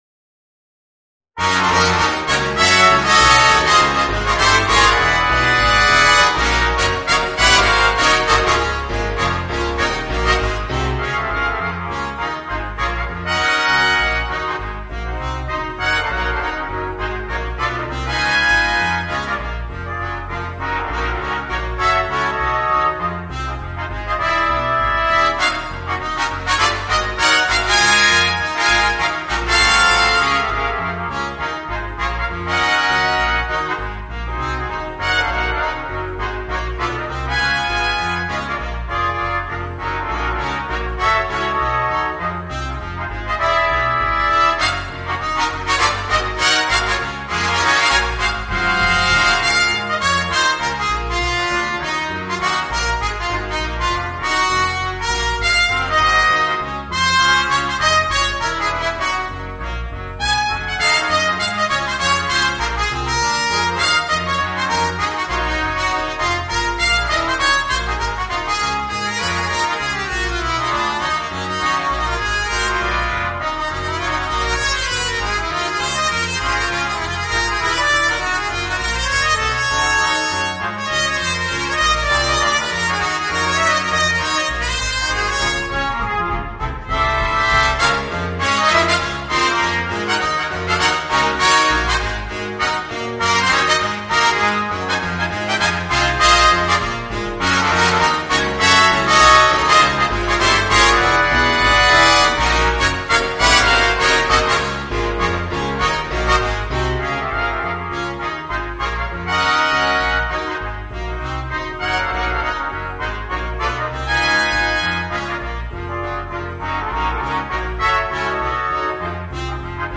Voicing: 5 Trumpets and Piano